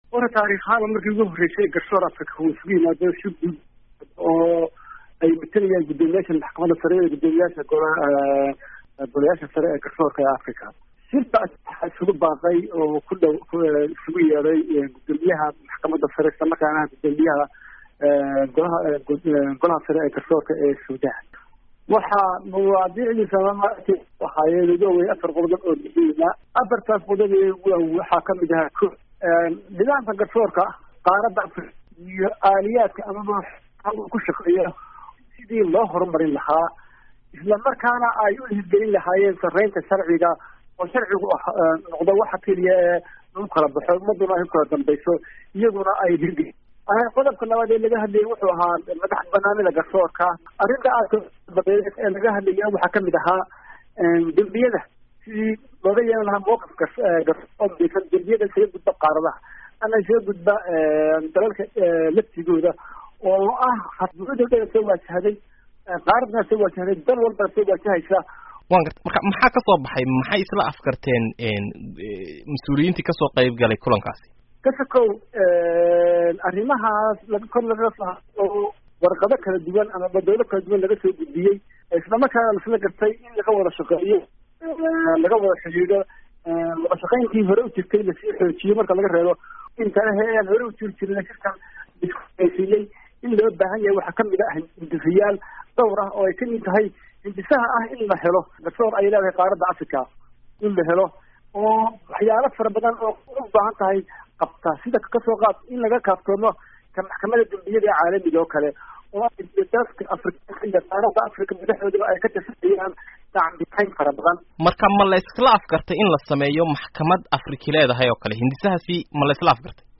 Wareysi: Guddoomiyaha Maxkamadda Sare